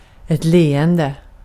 Ääntäminen
IPA : /smaɪl/